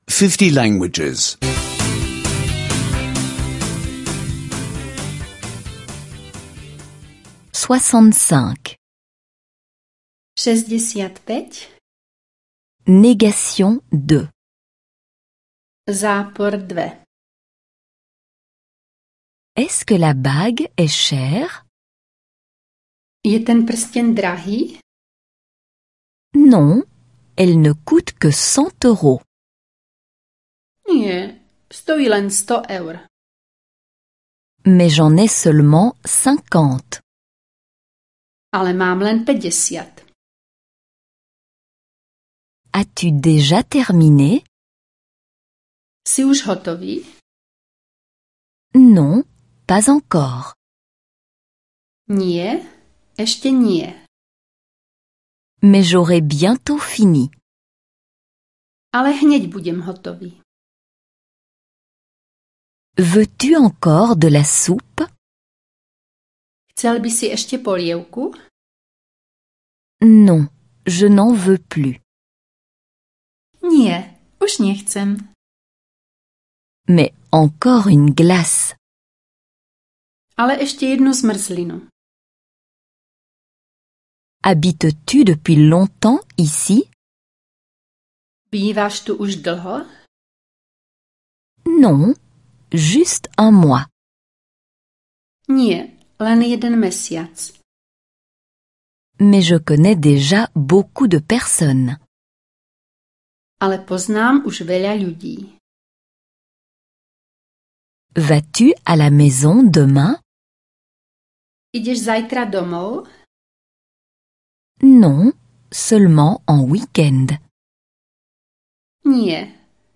Cours audio de slovaque (téléchargement gratuit)